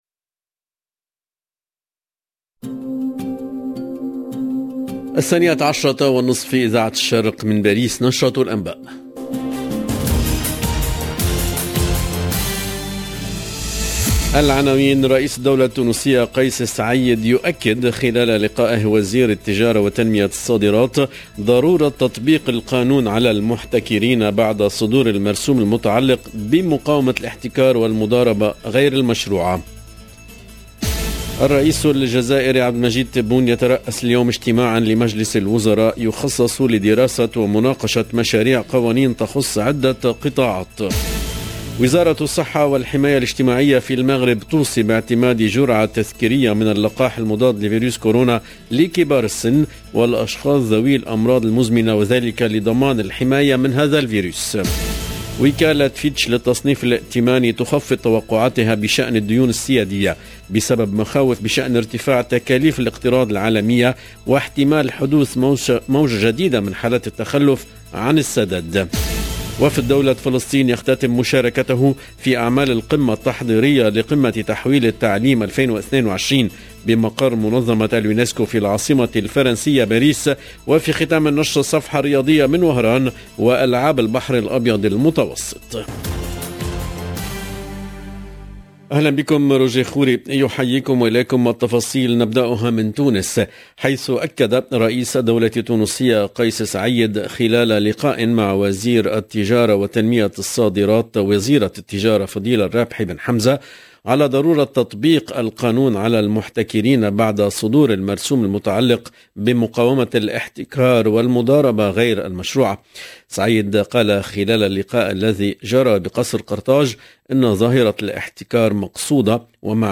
LE JOURNAL DE MIDI 30 EN LANGUE ARABE DU 3/07/22